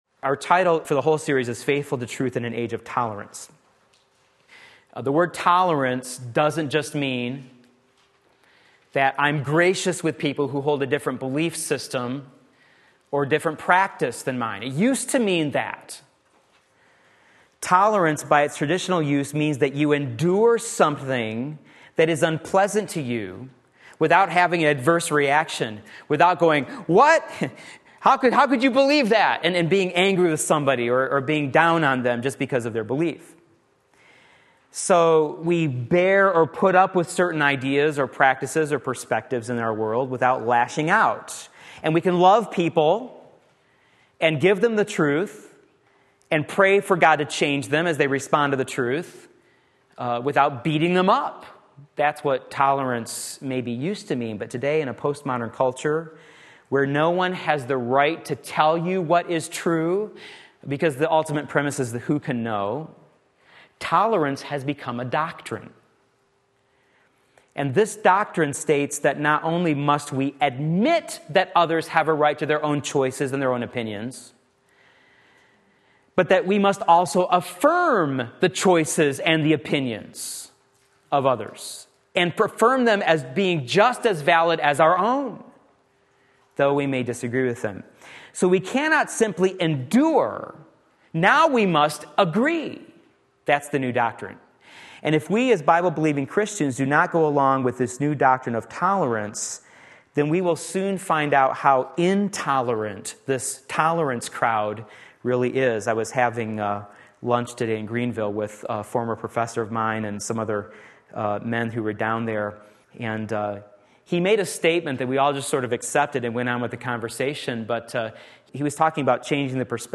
Sermon Link
Facing Temptation Like Jesus Did Matthew 4:1-11 Wednesday Evening Service